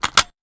weapon_foley_pickup_02.wav